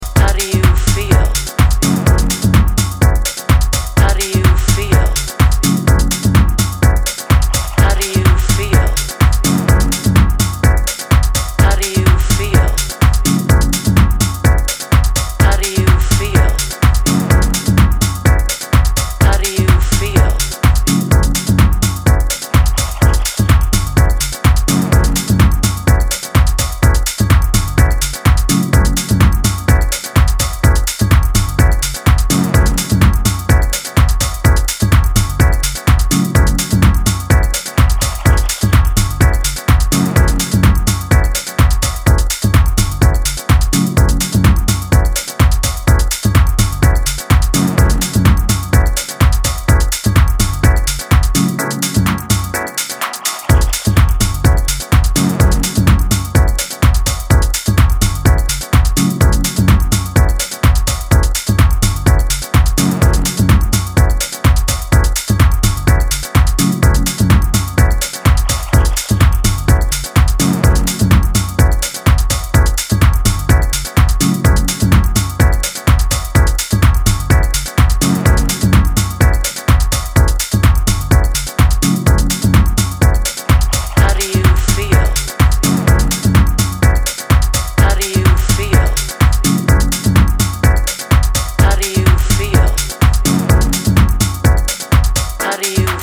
tech house